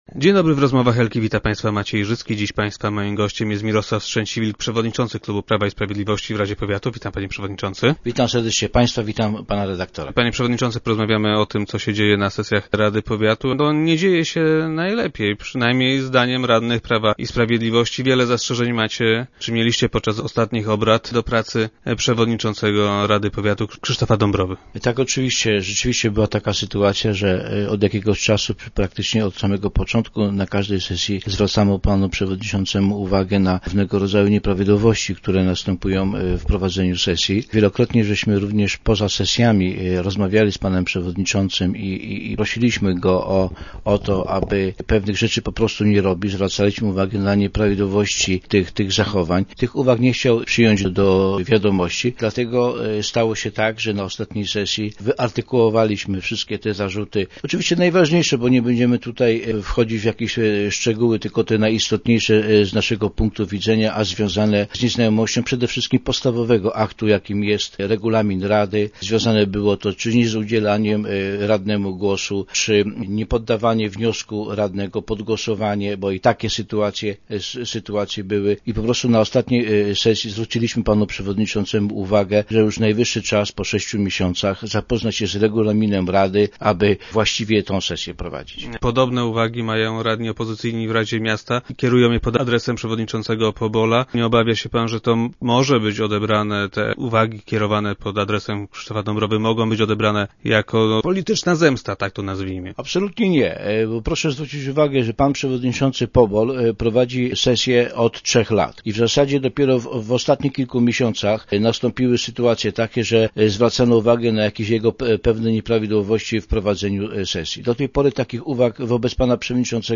Jak powiedział Mirosław Strzęciwilk, szef klubu radnych PiS i dzisiejszy gość Rozmów Elki, przewodniczący powinien zmienić swoje zachowanie i w końcu poznać regulamin rady.